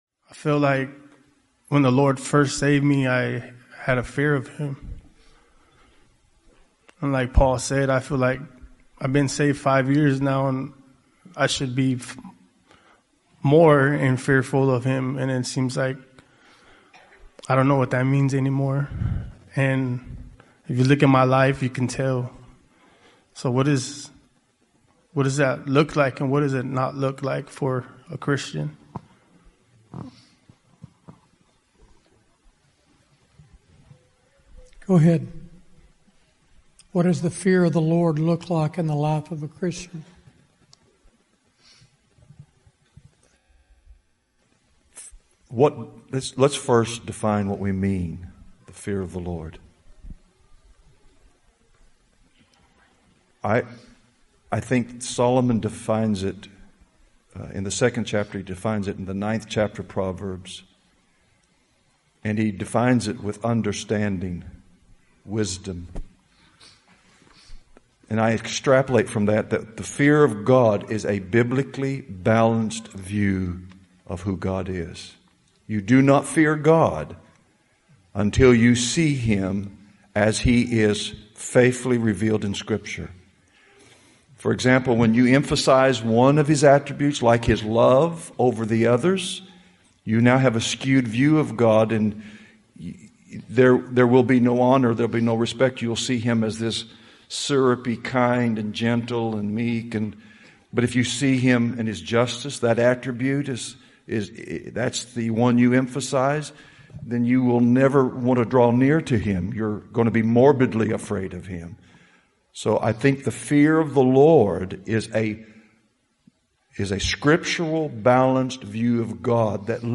This was taken from the question and answer session at the 2021 Fellowship Conference. 0:00